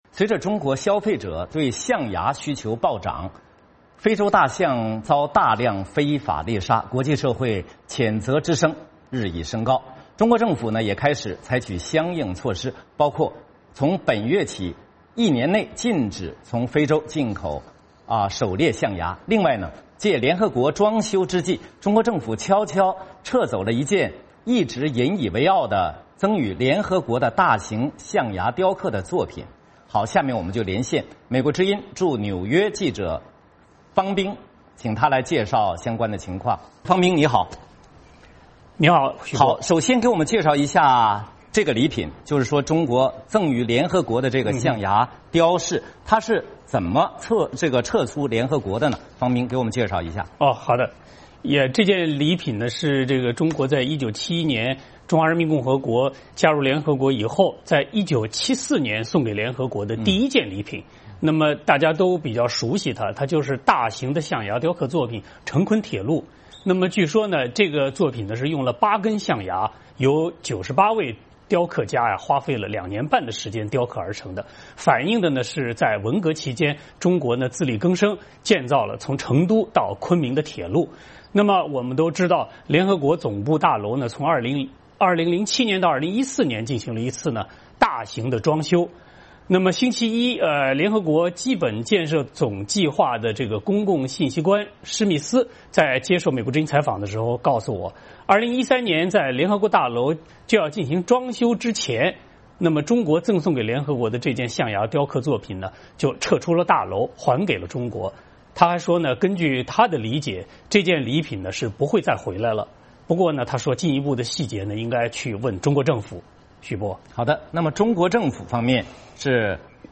VOA连线：中国撤走联合国象牙雕塑